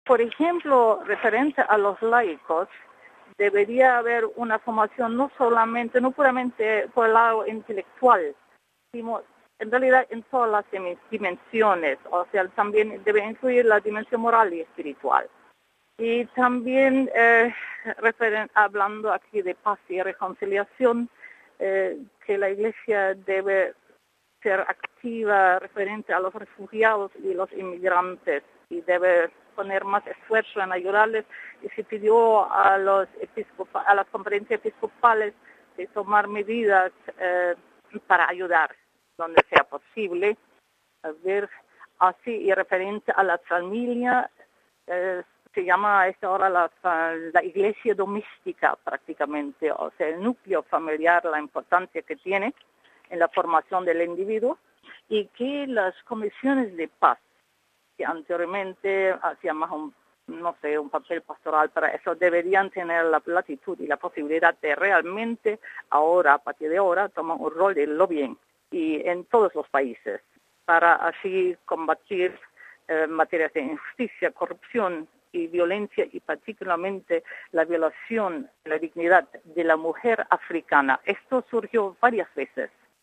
¿Y nos podría anticipar algunas de las sugerencias que han quedado recogidas en el documento final?